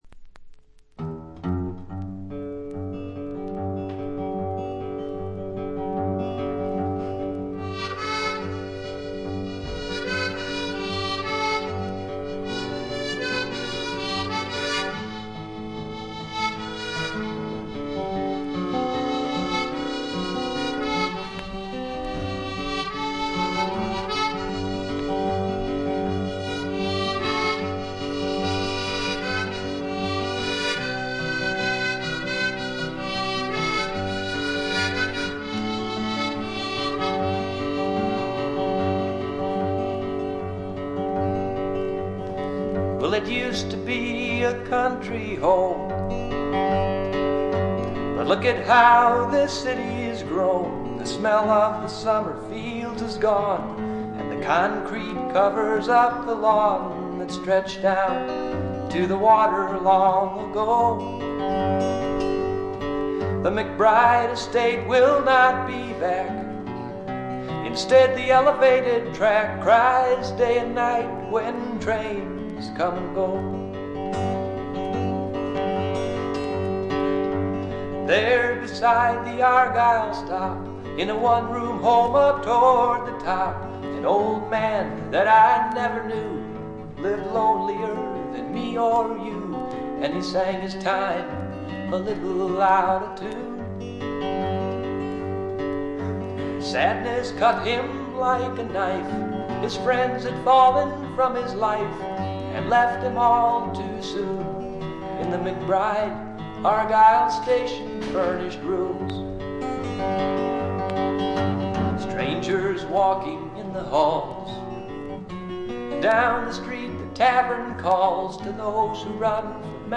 細かなチリプチのみ。
試聴曲は現品からの取り込み音源です。